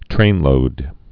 (trānlōd)